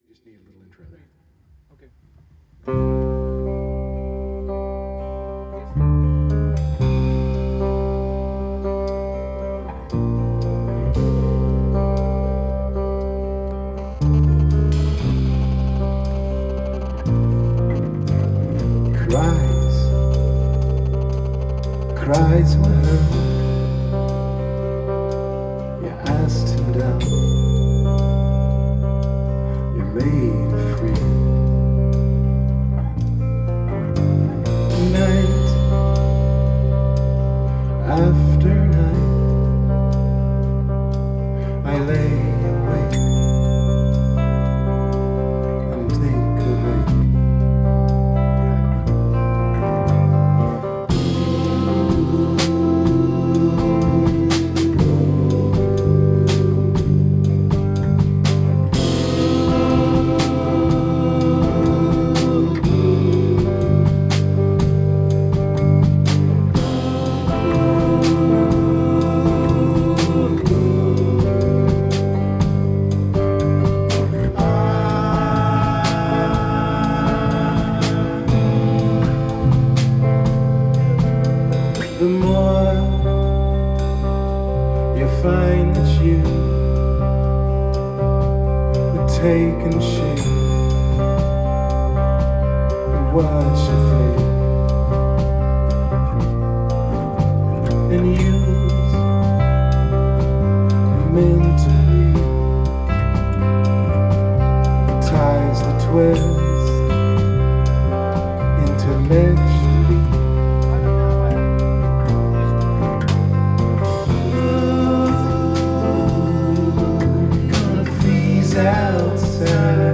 Rehearsals 6.9.2013